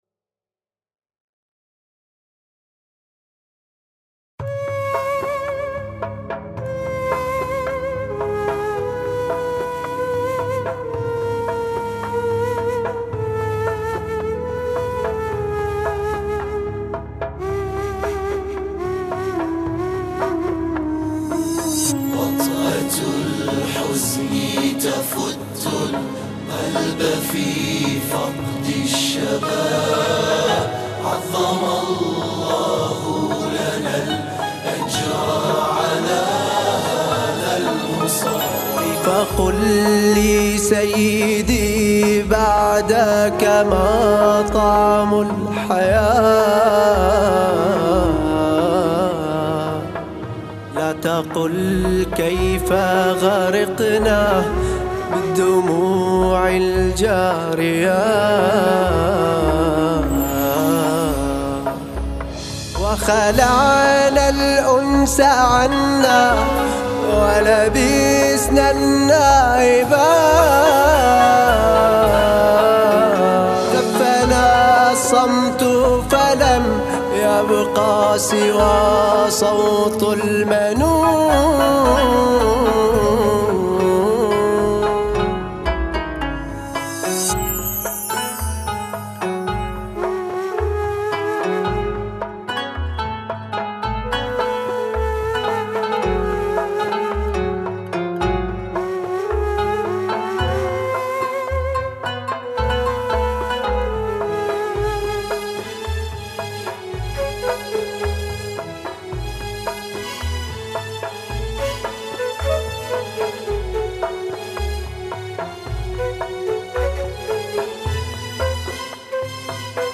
أناشيد بحرينية انشودة وطنية